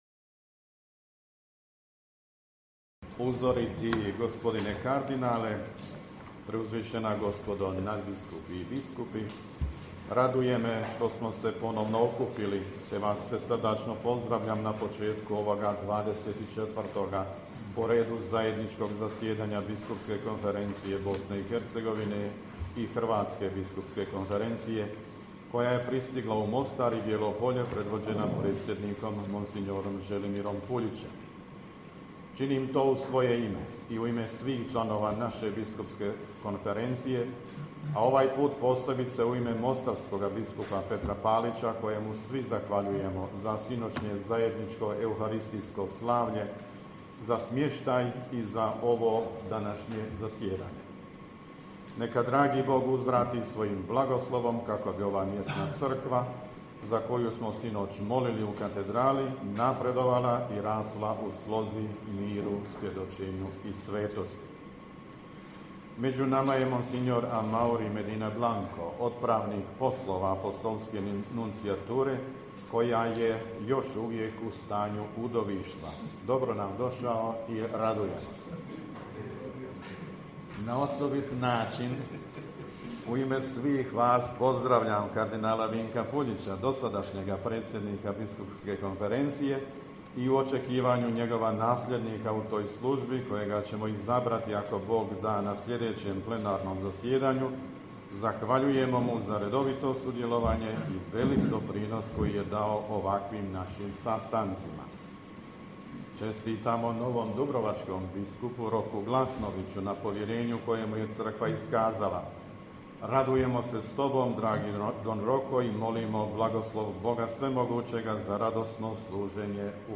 AUDIO: UVODNI POZDRAV NADBISKUPA VUKŠIĆA NA ZAJEDNIČKOM ZASJEDANJU BK BIH I HBK
XXIV. redovito godišnje zajedničko zasjedanje u duhovno-pastoralnom centru „Emaus“ u Bijelom Polju (Potoci) pokraj Mostara.
Na početku radnog jednodnevnog susreta uvodni pozdrav uputio je dopredsjednik BK BiH mons. Tomo Vukšić, nadbiskup metropolit vrhbosanski i apostolski upravitelj Vojnog ordinarijata u BiH.